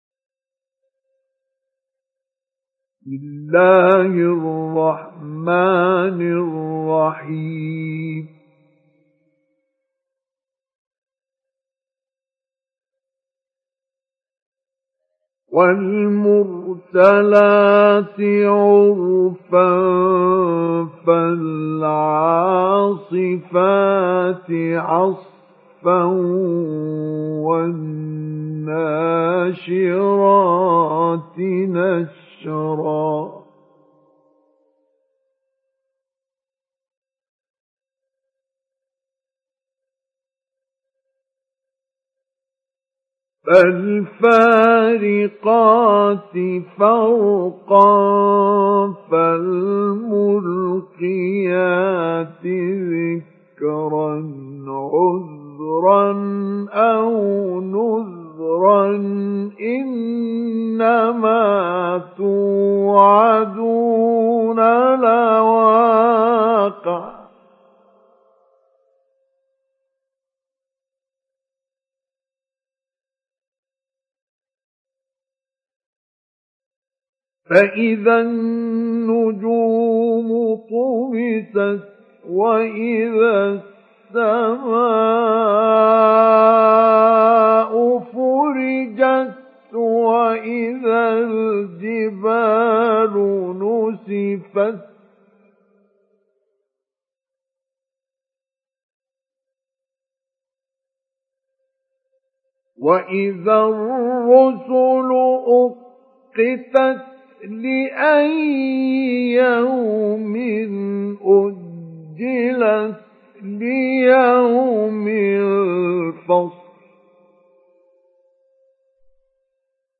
سُورَةُ المُرۡسَلَاتِ بصوت الشيخ مصطفى اسماعيل